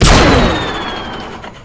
gun3.ogg